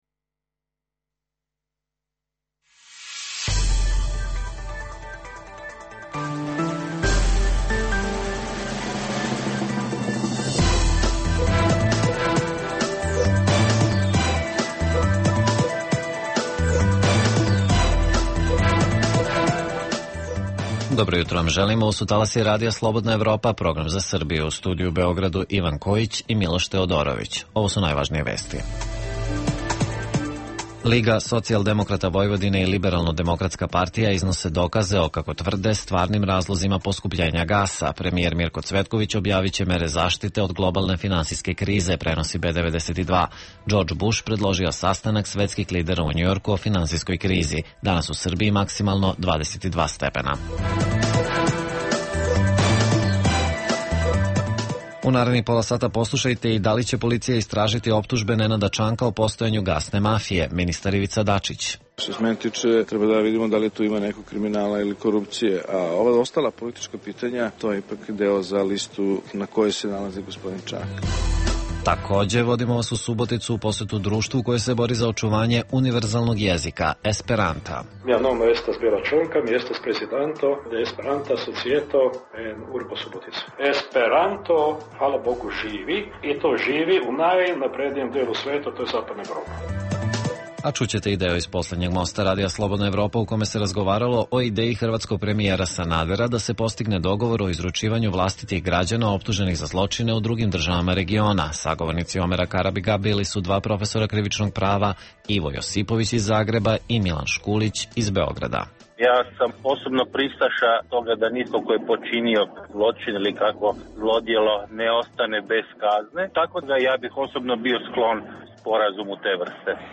Lider Lige socijaldemokrata Vojvodine Nenad Čanak uživo u emisiji odgovara na pitanja vezana za pretnju koju je uputio Vladi Srbije, obećavajući da će je oboriti ukoliko ne dobije objašnjenja vezana za postojanje gasne mafije u Srbiji zbog koje je, po njemu, došlo do drastičnog poskupljenja gasa. Takođe, donosimo i priču o nadama Čačana da će zahvaljujući dolasku Fiata u Kragujevac i ovaj grad profitirati, kao i reportažu o udruženju esperantista iz Subotice koji tvrde da vreme univerzalnog jezika tek dolazi.